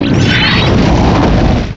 pokeemerald / sound / direct_sound_samples / cries / volcarona.aif